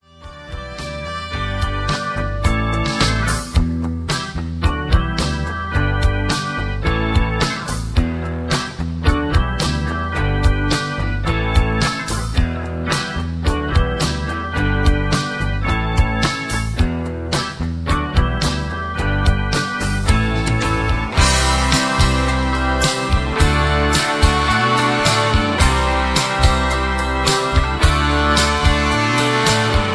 Version-2 Key-Em) Karaoke MP3 Backing Tracks
Just Plain & Simply "GREAT MUSIC" (No Lyrics).